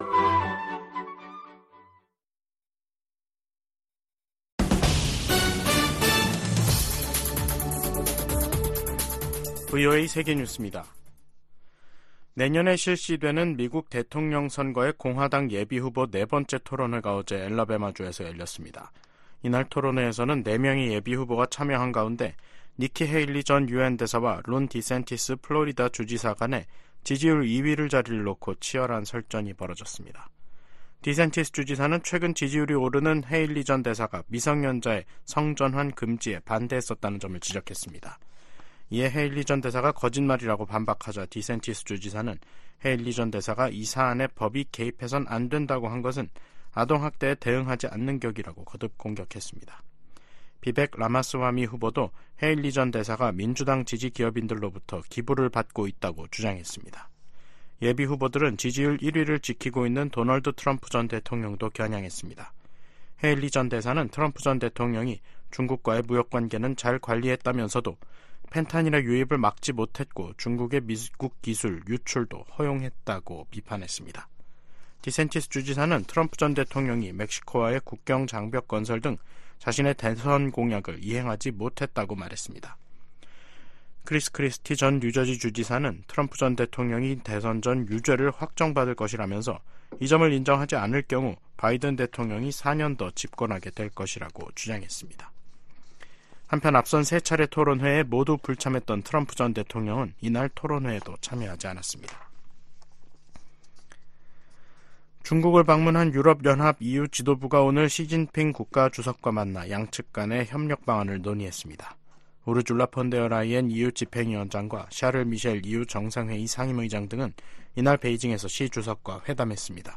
VOA 한국어 간판 뉴스 프로그램 '뉴스 투데이', 2023년 12월 7일 2부 방송입니다.